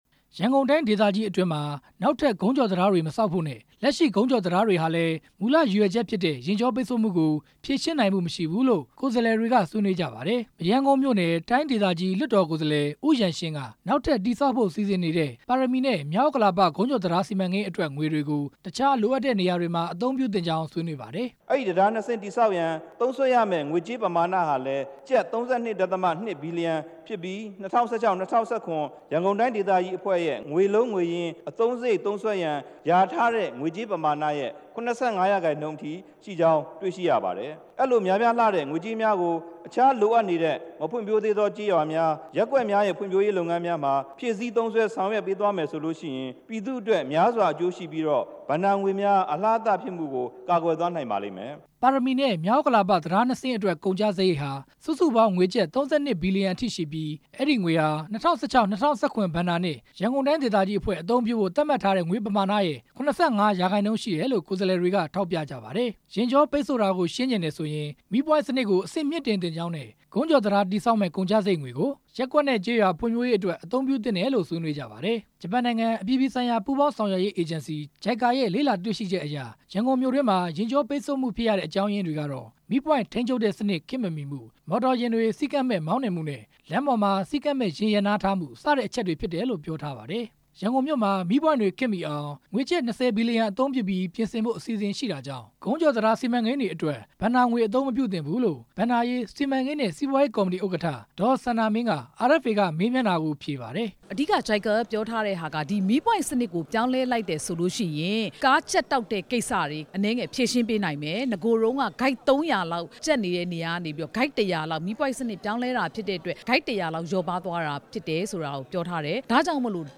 ရန်ကုန်တိုင်းဒေသကြီး လွှတ်တော်မှာ ဆွေးနွေးချက်တွေအကြောင်း RFA သတင်းထောက်